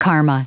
Prononciation du mot karma en anglais (fichier audio)